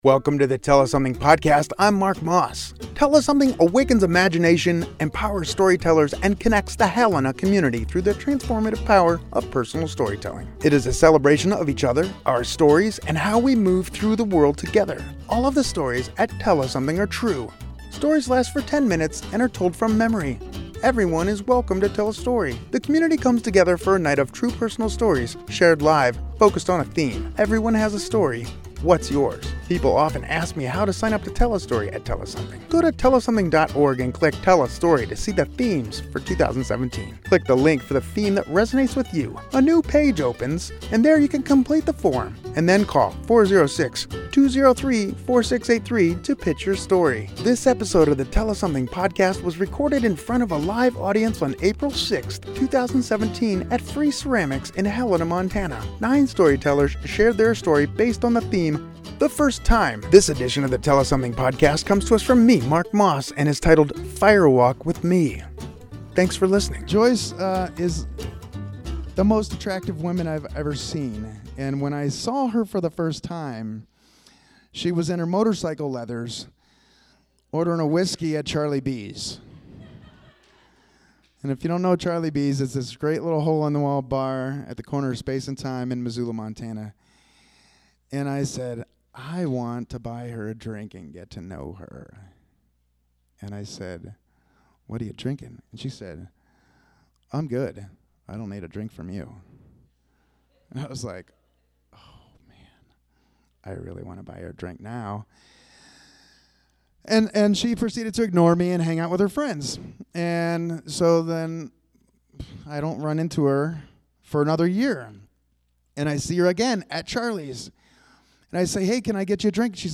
This episode of Tell Us Something was recorded in front of a live audience on April 6, 2017 at Free Ceramics in Helena, MT. 9 storytellers shared their story. The theme was “The First Time”.